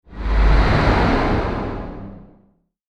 暗黒エネルギー.mp3